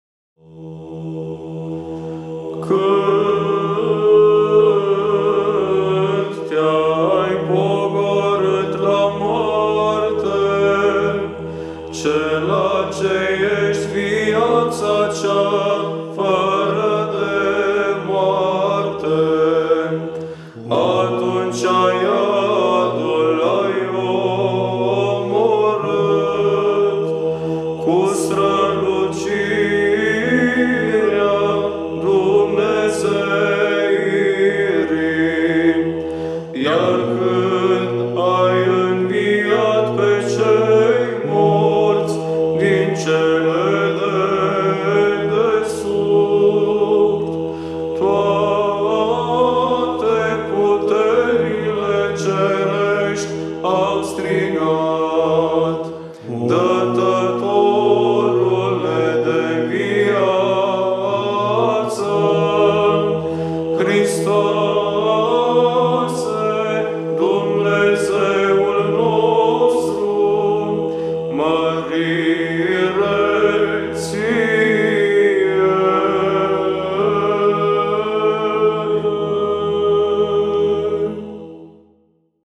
Glasurile bisericești – varianta de tradiție bizantină TRIFON LUGOJAN
GLAS II
Tropar
05.-Glas-II-Tropar.mp3